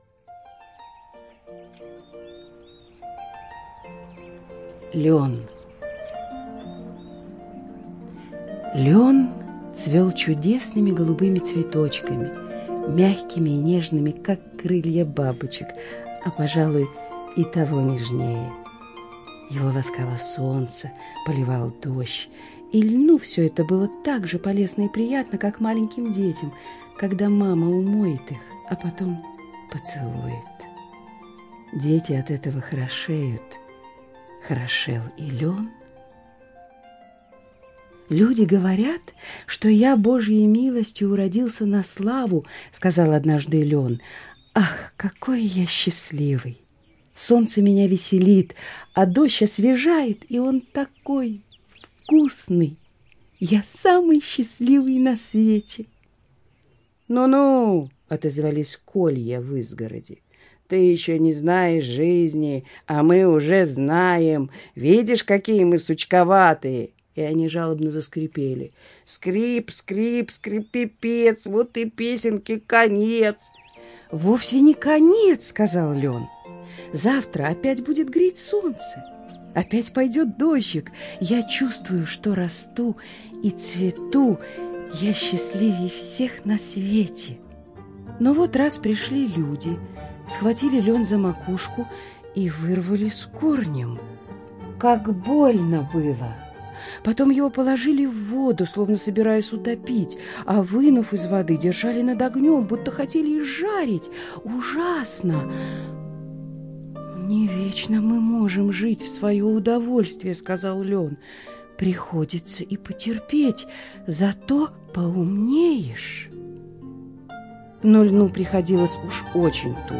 Лён - аудиосказка Андерсена.